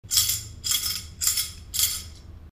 CYBELE.SISTRE
06_Cybele_Sistre.mp3